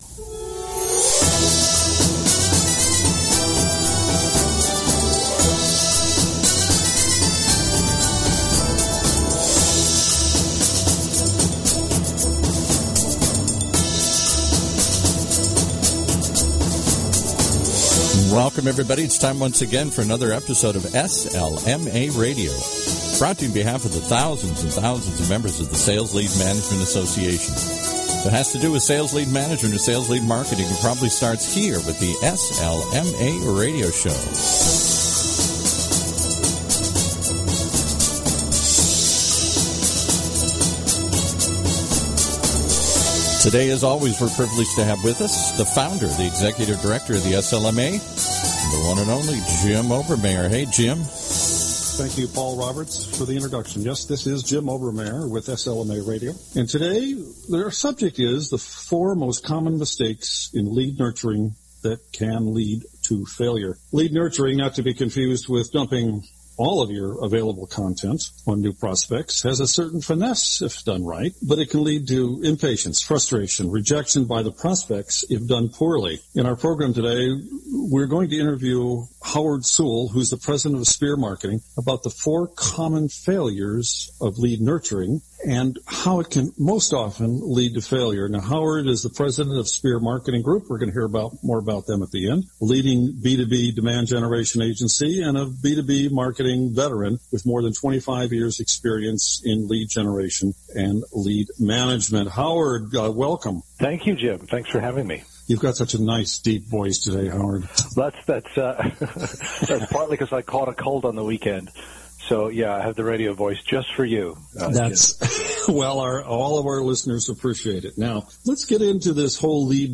Lead Nurturing, not to be confused with dumping all your available content on new prospects, has a certain finesse if done right, but it can lead to impatience, frustration and rejection by prospects if done poorly. In this program we interview